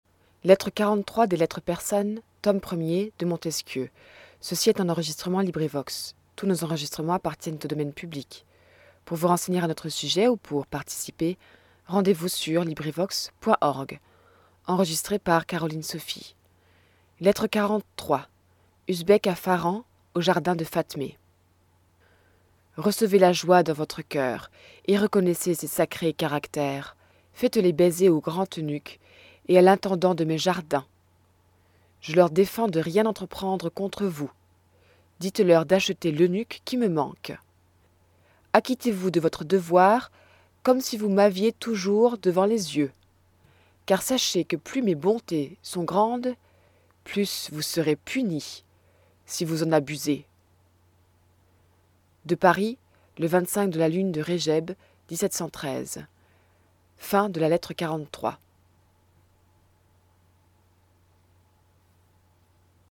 English: Les Lettres persanes , by Montesquieu, in French. LibriVox recording by volunteers. Lettre 43. Usbek à Pharan, aux jardins de Fatmé .